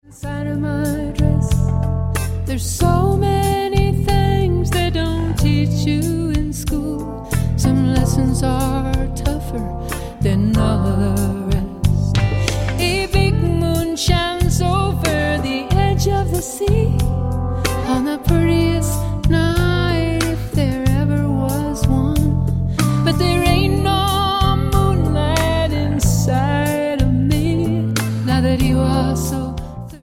Genre: Pop Rock
vocals
guitar, lap steel guitar, Appalachian fiddle
synthesizer, keyboards
drums
saxophone
bass